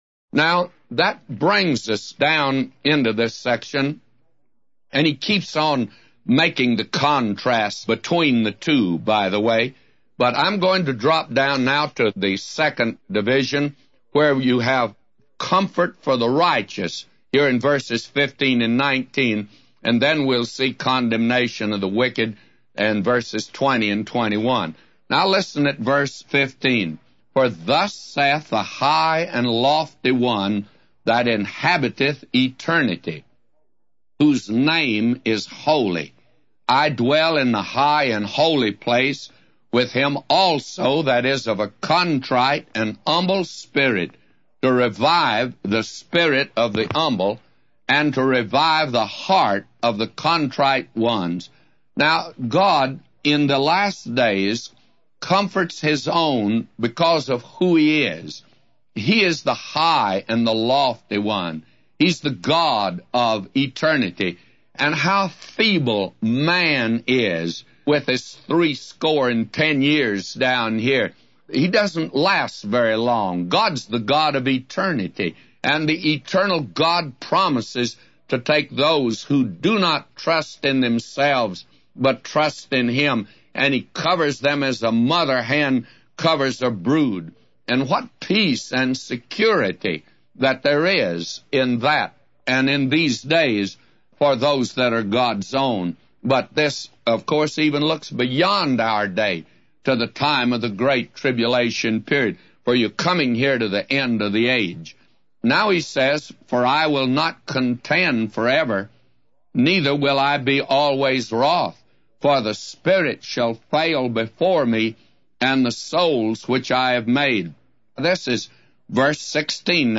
A Commentary By J Vernon MCgee For Isaiah 57:15-999